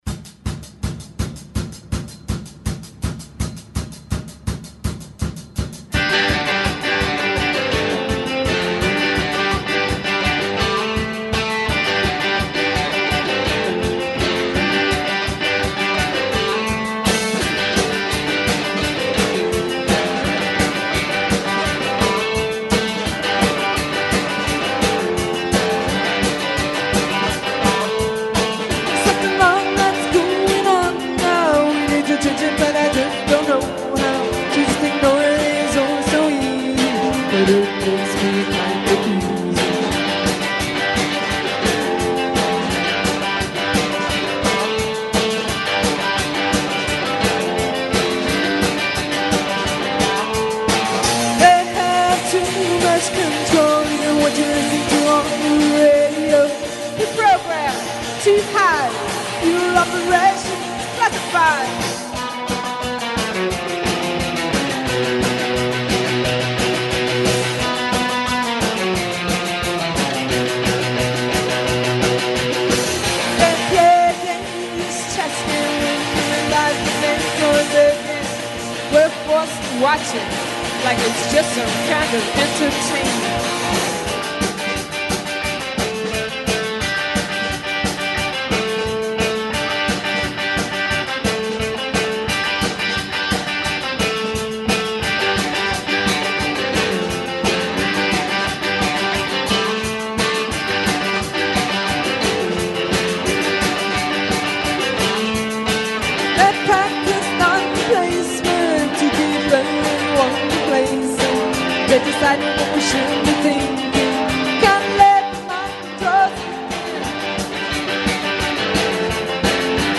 This song was recorded in 2004 on WFMU, Jersey City, NJ.